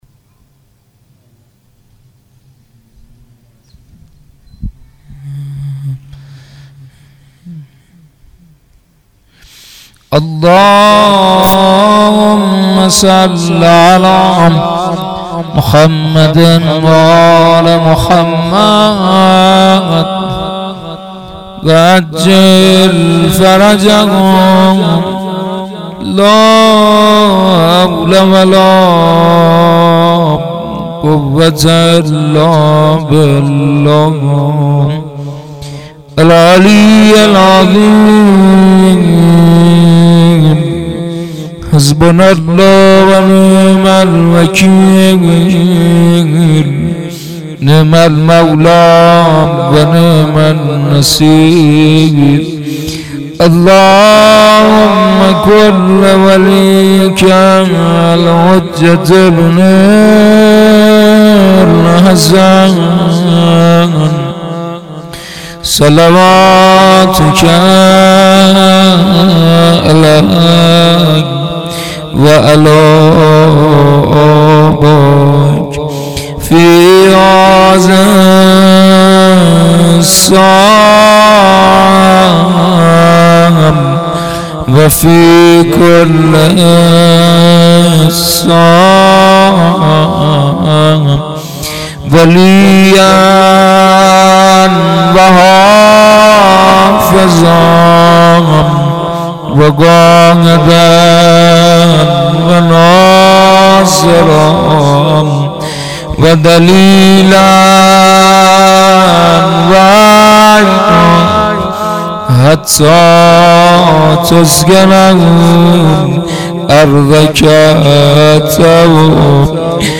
روضه زمینه و شور زیارت عاشورا مناجات با امام زمان (عج)